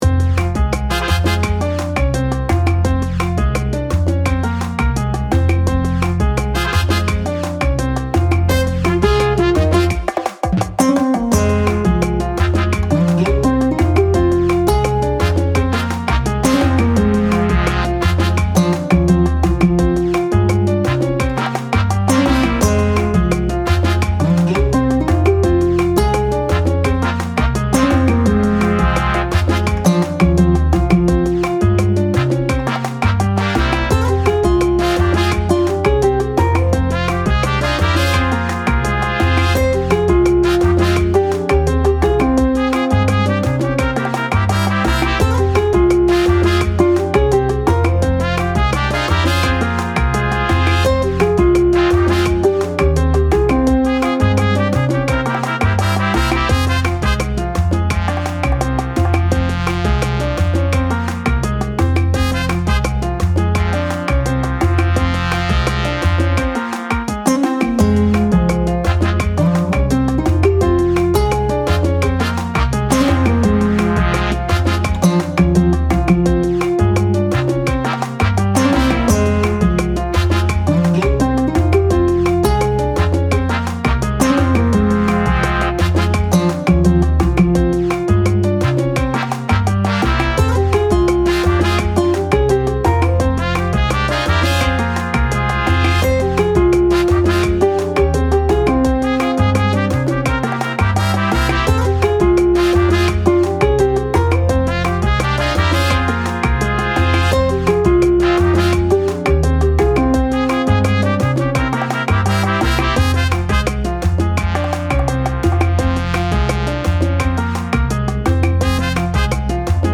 ラテン系ジャンルのサルサのリズムを取り入れたちょっぴり情熱的なBGMです。